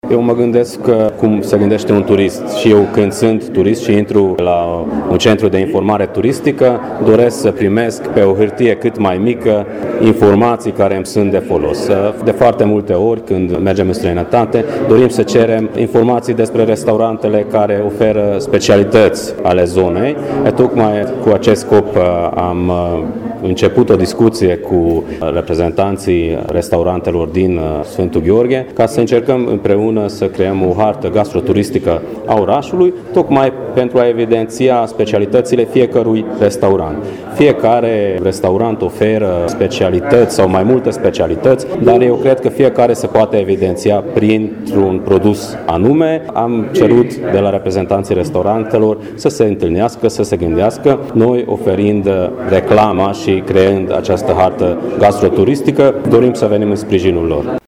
Vicepreşedintele Consiliului, Gruman Robert a declarat că scopul acestui demers este de a veni în sprijinul turiştilor şi al întreprinzătorilor locali, dar şi de a promova gastronomia locală: